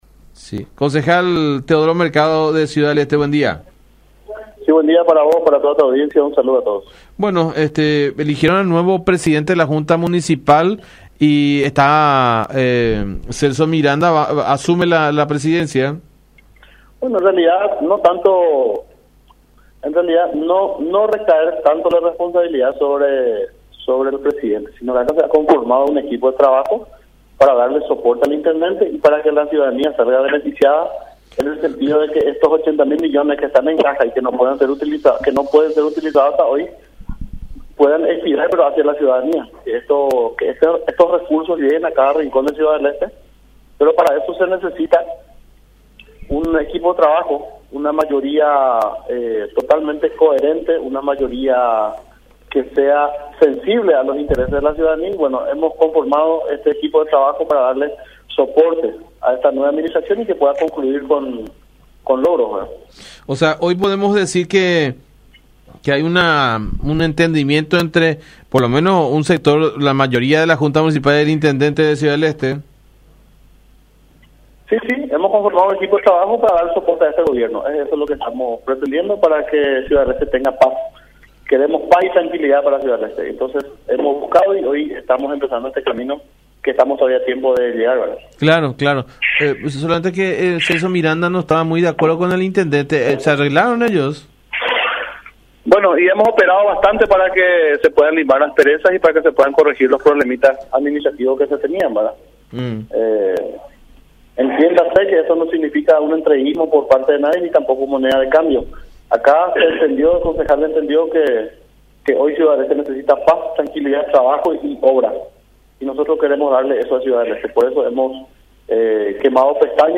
El concejal Teodoro Paredes, quien resultó elegido vicepresidente primero, explicó que el cambio de mesa directiva fue parte de una estrategia político para lograr un equilibrio con la administración del actual intendente, Miguel Prieto.
21-Teodoro-Mercado-Concejal-de-CDE.mp3